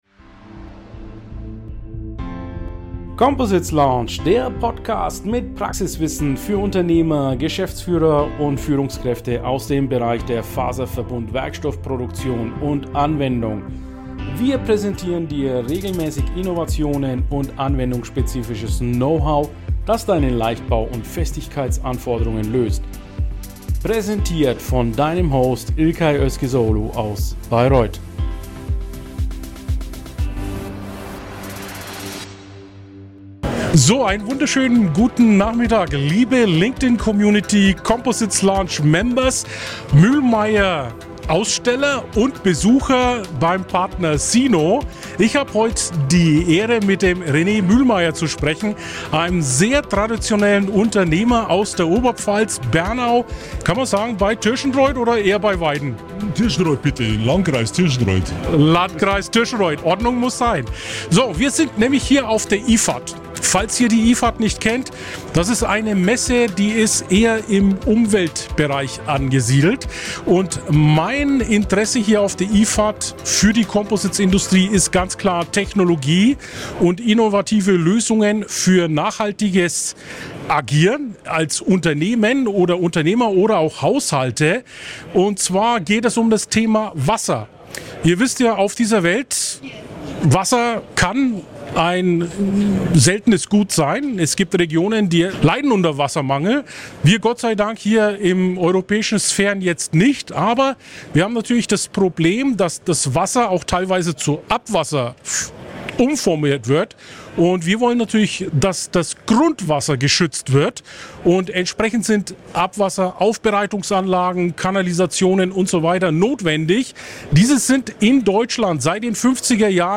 Heute sprechen wir darüber wie Composites unser Grundwasser schützen und die Frischwasserversorgung sicherstellen. Wir waren am 13.5.2024 auf der IFAT 2024.